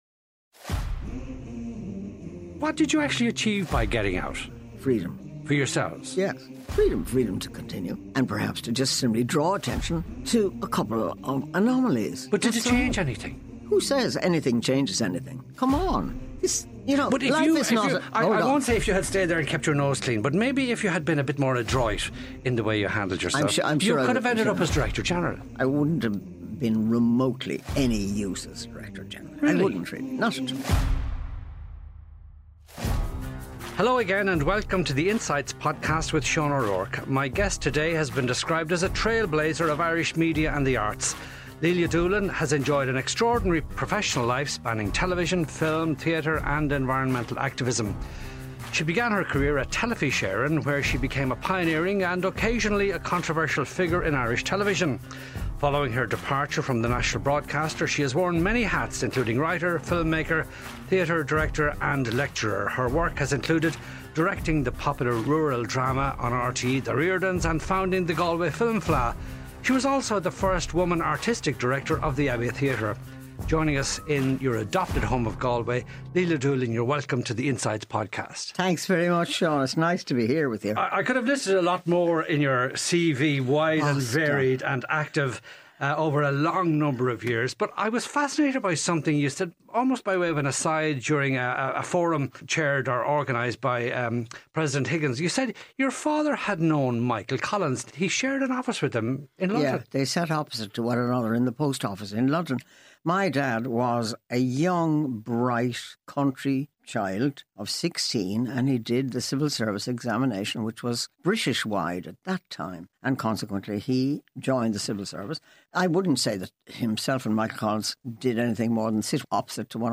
One-to-one conversations between Sean O'Rourke and well-known individuals. Featured guests are prominent in public service, culture, sport and business and these long-form conversations explore their motivations, achievements and regrets; in short, what made them the people they are.